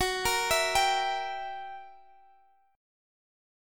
F#7b9 Chord (page 2)
Listen to F#7b9 strummed